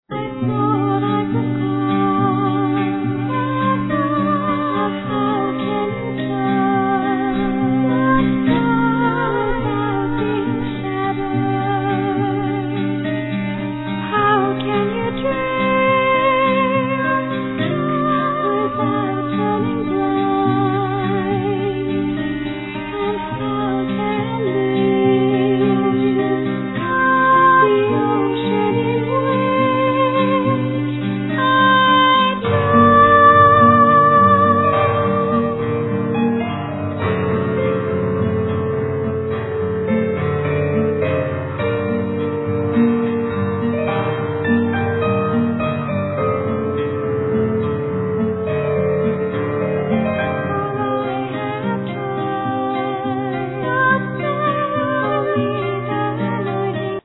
Violin
Flute
Drums, Percussions, Bass, Vocals ,All other instruments